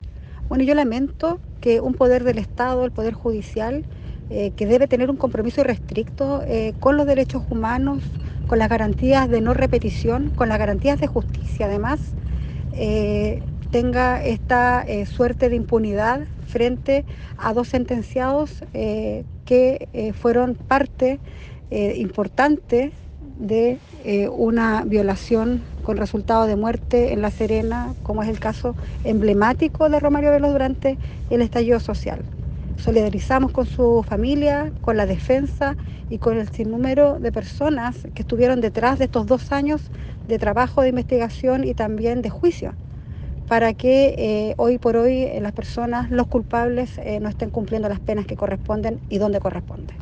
La diputada Nathalie Castillo dijo lamentar